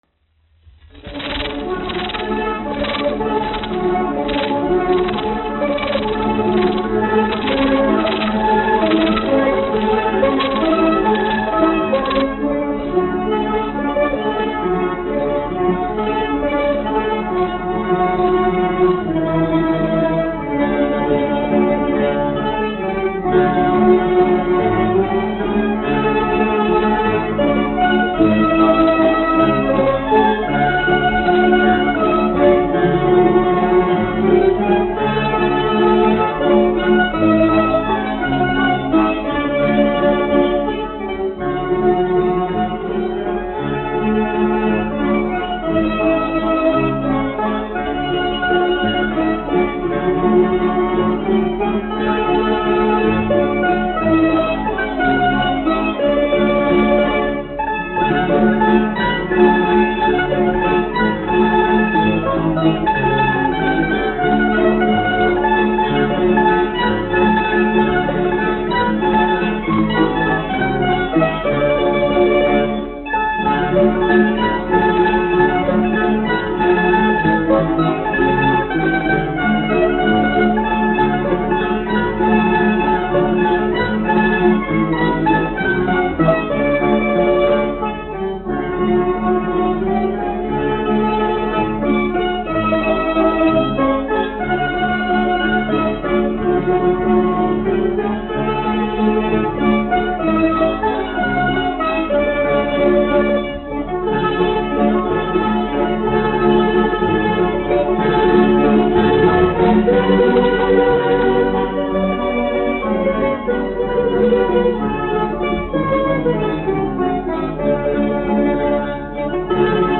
1 skpl. : analogs, 78 apgr/min, mono ; 25 cm
Orķestra mūzika
Skaņuplate